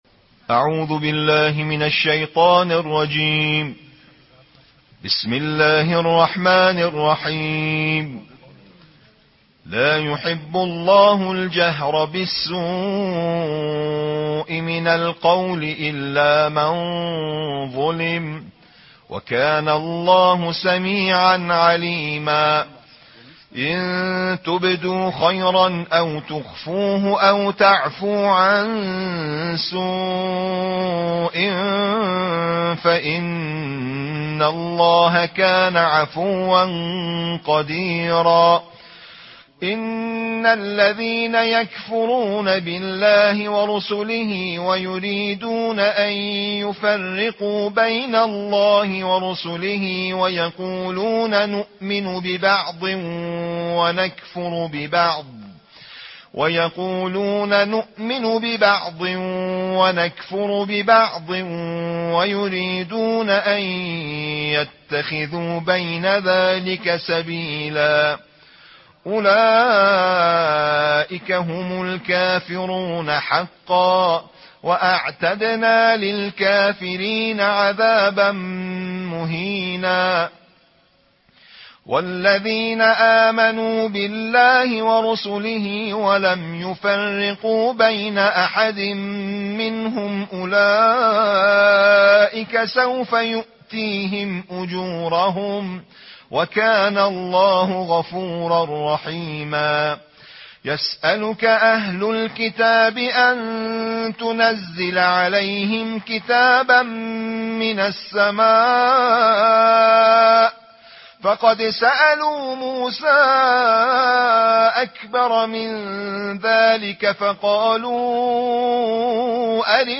نړیوال قاریان د قرآن کریم د شپږمې(۶) سپارې یا جزوې د ترتیل قرائت
دغه تلاؤتونه د تیر کال(۱۴۴۵قمری کال) د روژې د مبارکې میاشتې په شپو ورځو کې د تهران د خاتم الانبیاء روغتون د کلتوری برخې له خوا د روغتون په لمانځه کوټې کې اجرا او ریکارډ شوی او د لومړي ځل لپاره خپریږي.